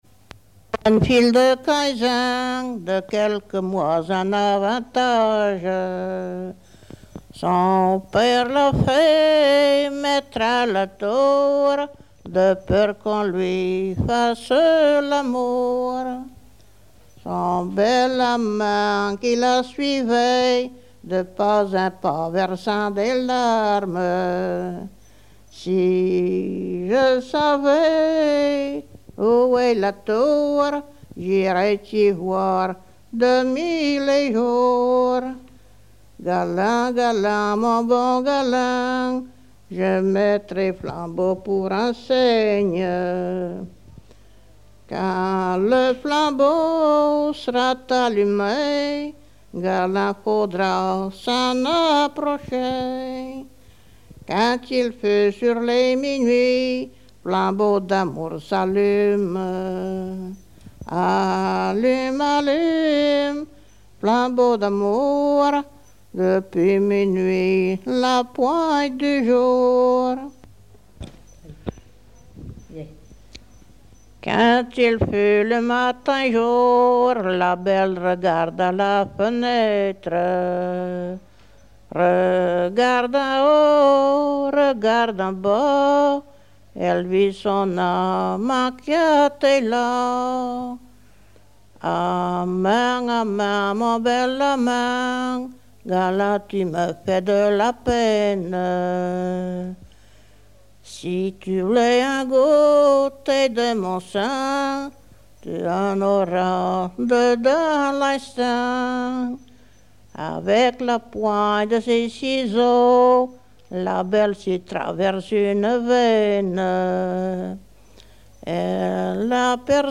Genre strophique
Chansons traditionnelles
Catégorie Pièce musicale inédite